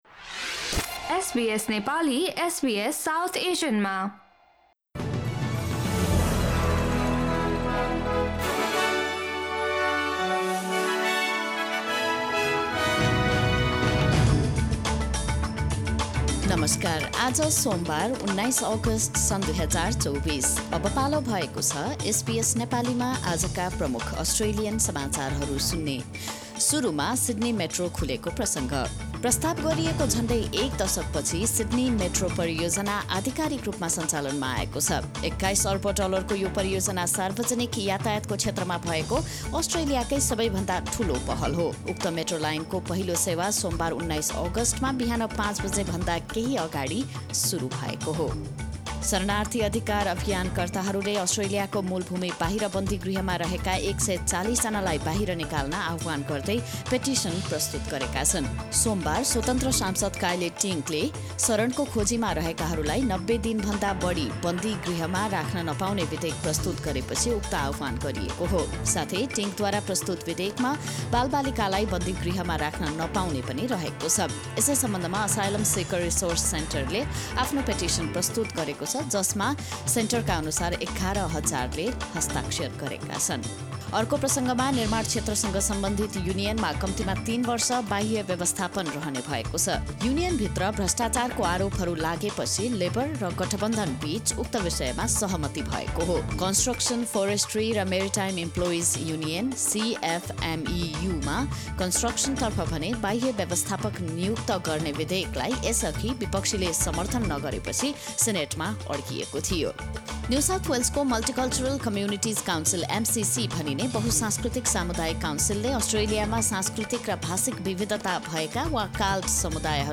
SBS Nepali Australian News Headlines: Monday, 19 August 2024